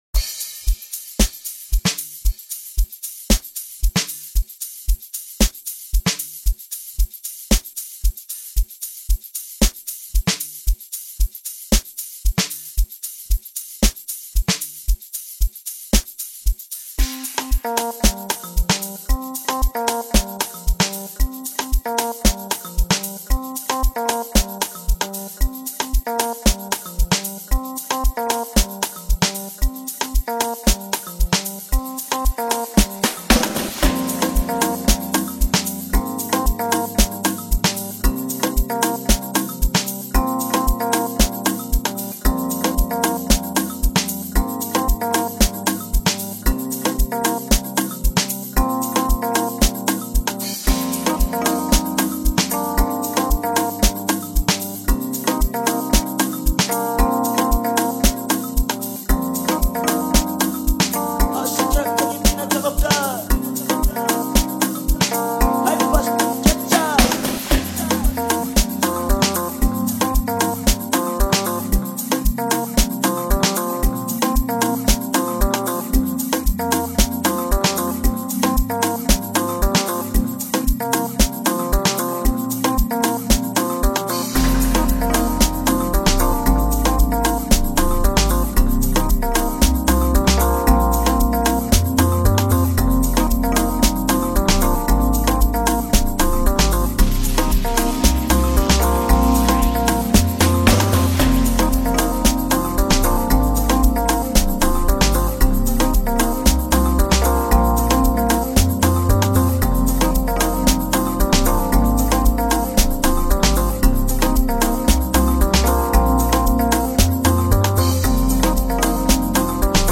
Amapiano
pure positivity reigns supreme throughout the polished track